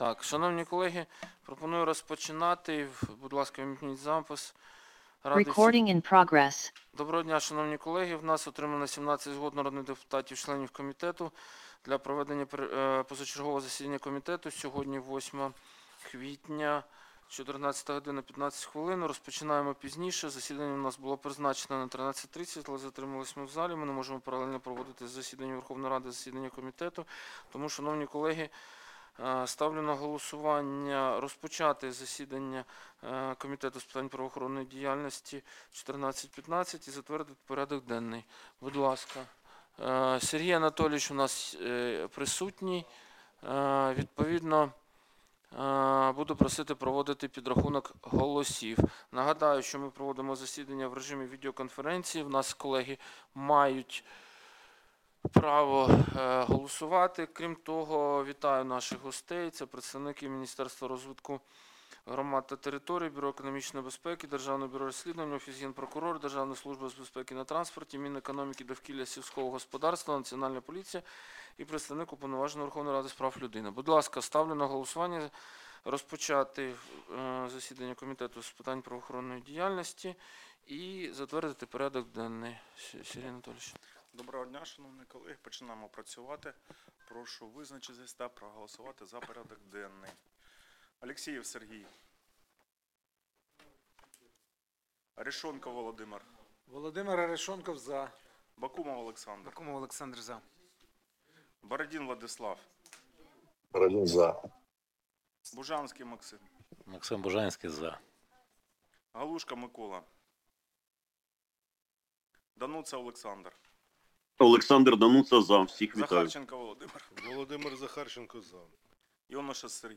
Аудіозаписи засідань Комітету за квітень 2026 року